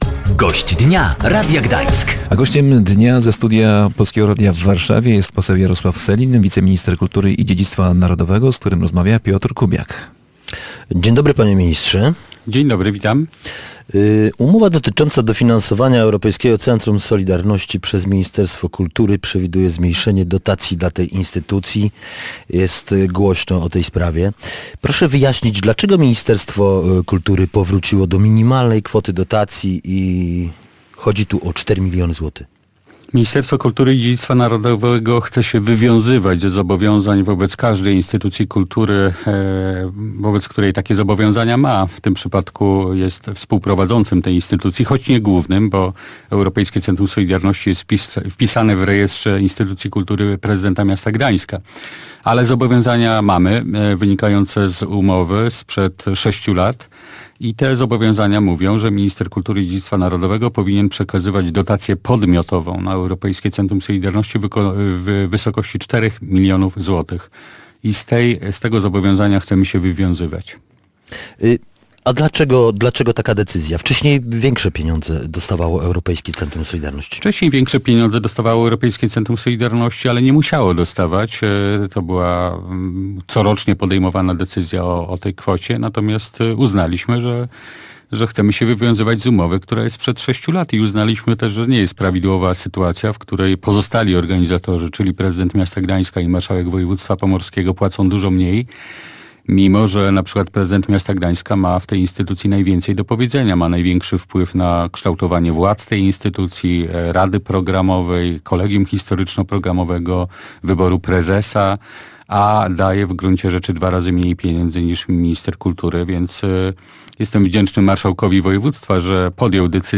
Gościem Dnia Radia Gdańsk był poseł Jarosław Sellin, wiceminister kultury i dziedzictwa narodowego.